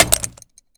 grenade_hit_05.WAV